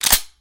ShotgunPump02.mp3